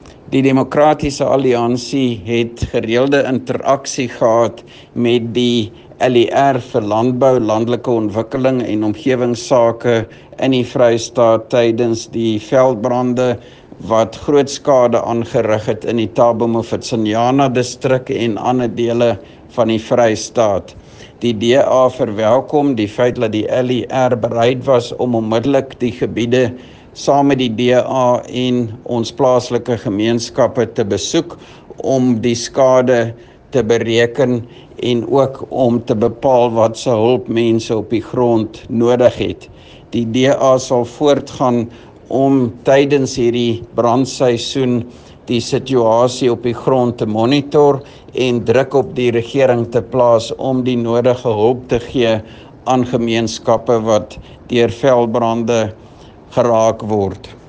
Afrikaans soundbites by Roy Jankielsohn MPL and images here,here,here and here